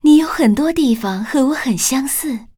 文件 文件历史 文件用途 全域文件用途 Dana_fw_01.ogg （Ogg Vorbis声音文件，长度2.6秒，103 kbps，文件大小：32 KB） 源地址:游戏语音 文件历史 点击某个日期/时间查看对应时刻的文件。 日期/时间 缩略图 大小 用户 备注 当前 2018年4月20日 (五) 02:45 2.6秒 （32 KB） 地下城与勇士  （ 留言 | 贡献 ） 源地址:游戏语音 您不可以覆盖此文件。